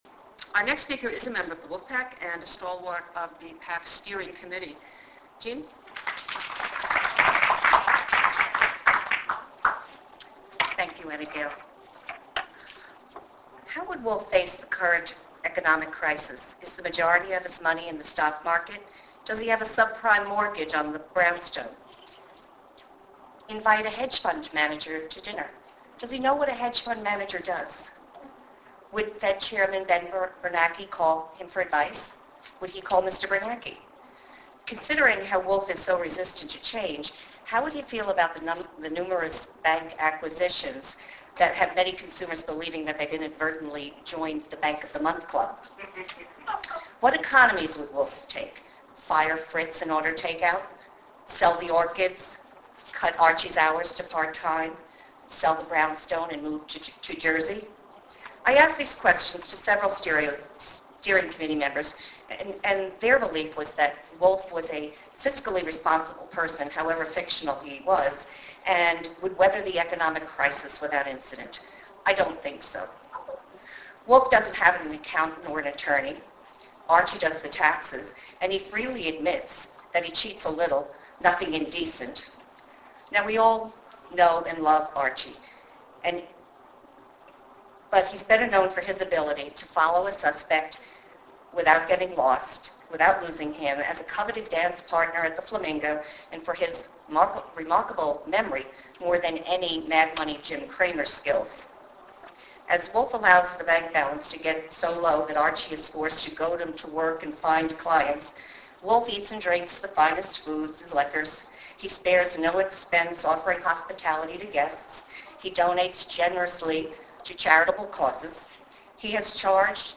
The Wolfe Pack 2008 Black Orchid Weekend includng Book Discussion, Assembly presentation, banquet with keynote speaker Frank D. Gilroy, and brunch
Date/Time: Saturday, Dec. 6, 2008 -- 2:00 p.m. Location: Hotel Jolly Madison Towers (Madison Ave. & E. 38th St.)